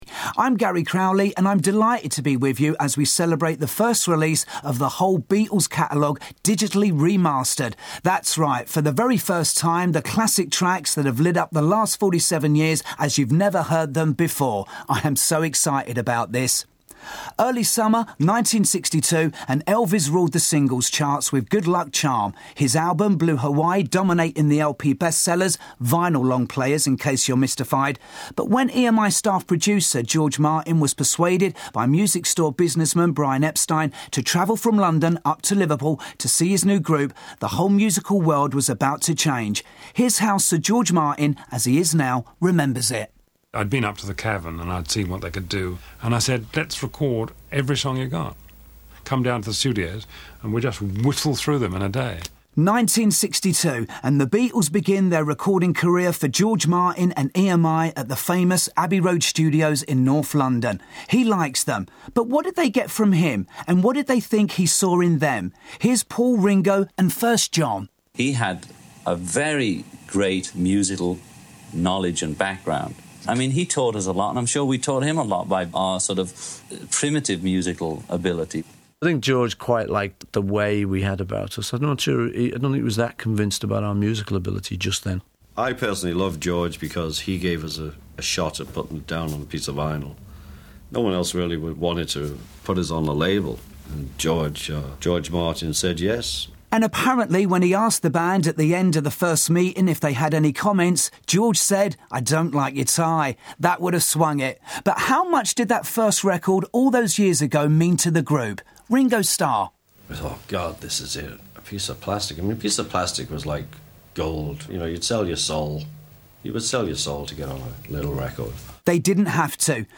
One very interesting thing is that some of the music tracks are preceded by original studio banter by the band that I can only presume comes from the The Beatles Rock Band game, also released on 09.09.09.  I don’t have the game, but I’ve read that they use previously unreleased studio chat and out-takes extensively to make the experience of playing it more realistic.